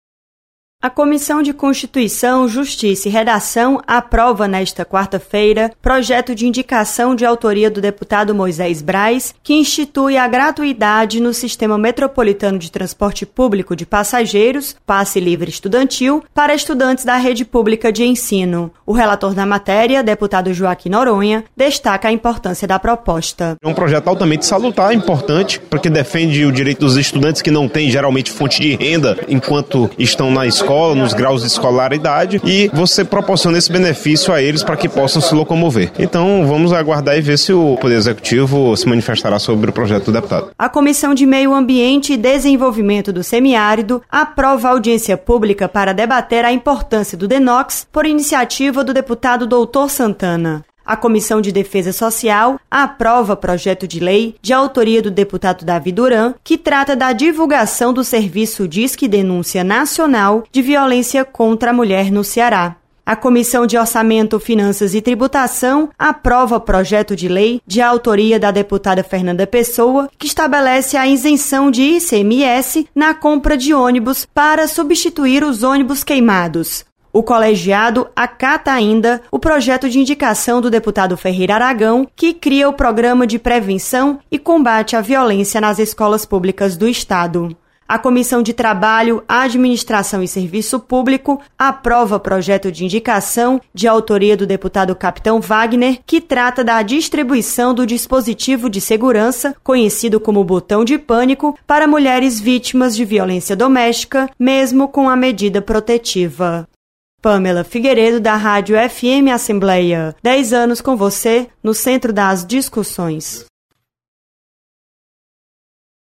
Acompanhe o resumo das comissões técnicas permanentes com a repórter